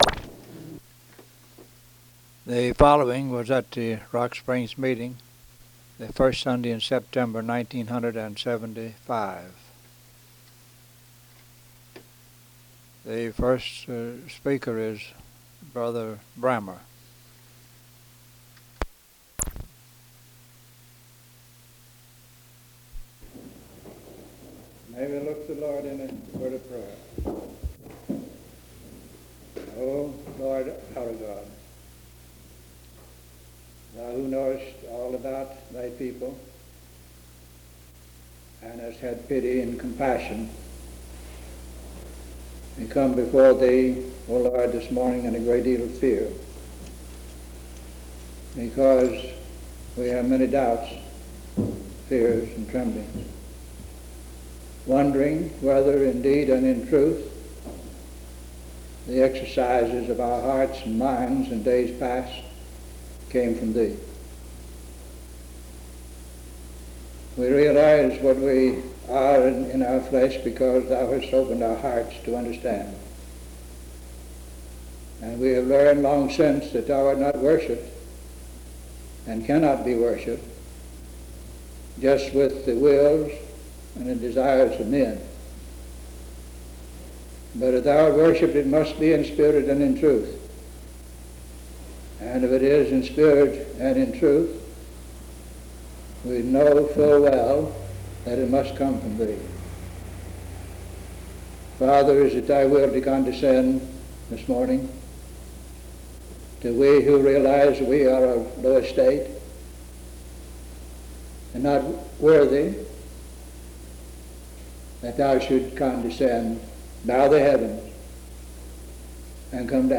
Sermons
at Rock Springs Church